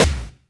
DIP SNR.wav